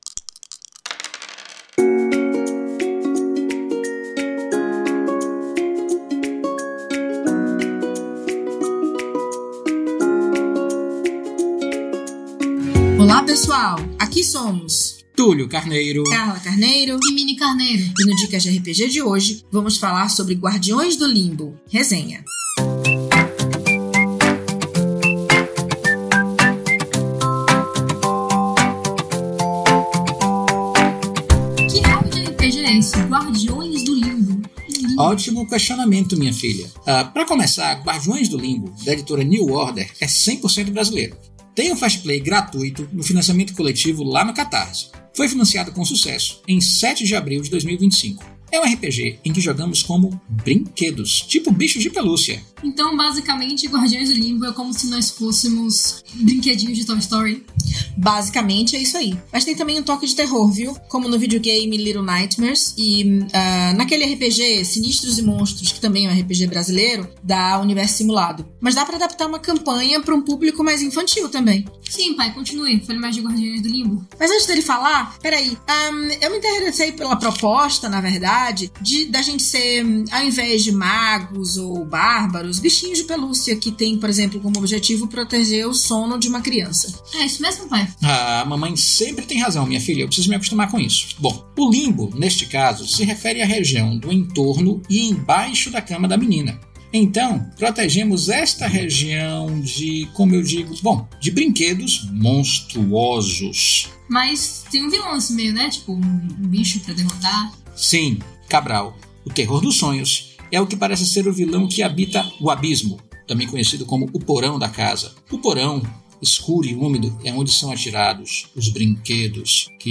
Com as vozes de:
O Dicas de RPG é um podcast semanal no formato de pílula que todo domingo vai chegar no seu feed.